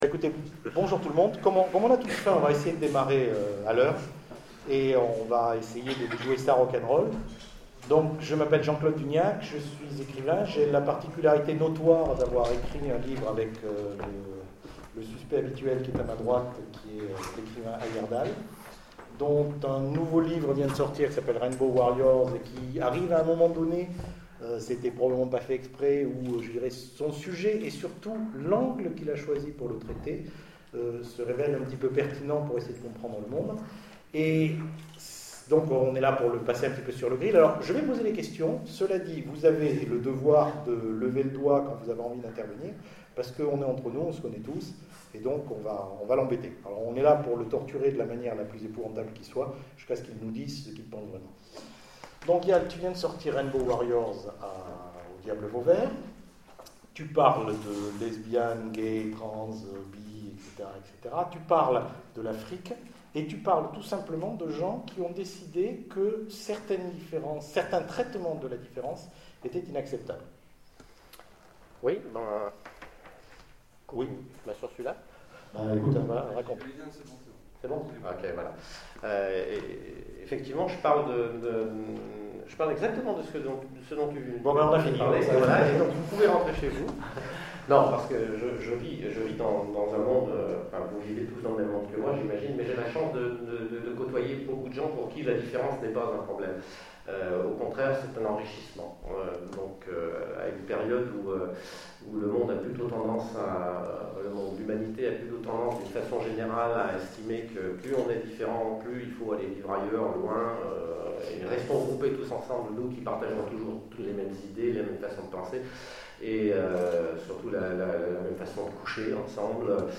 Etonnants Voyageurs 2013 : Conférence Les univers d'Ayerdhal
Etonnants Voyageurs 2013 : Conférence Les univers d'Ayerdhal Télécharger le MP3 EV Ayerdhal - diaporamas à lire aussi Yal Ayerdhal Genres / Mots-clés Rencontre avec un auteur Conférence Partager cet article